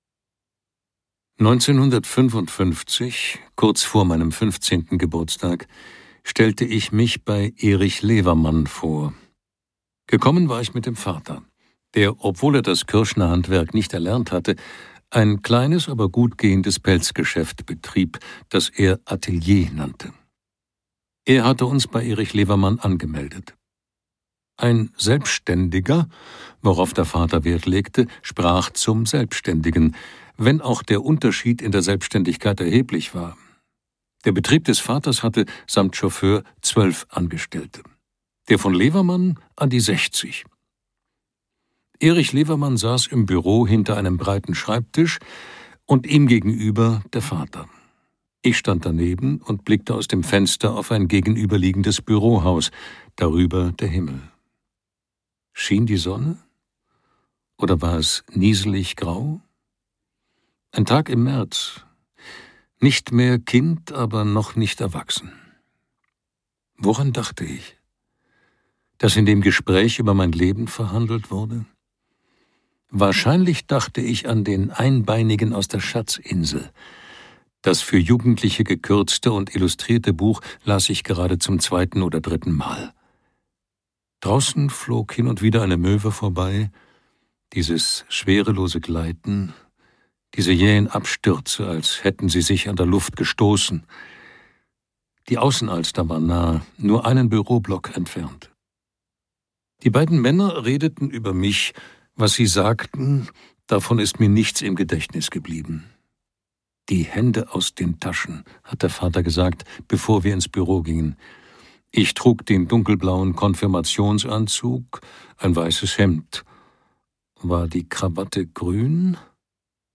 Alle meine Geister Uwe Timm (Autor) Gert Heidenreich (Sprecher) Audio-CD 2023 | 1.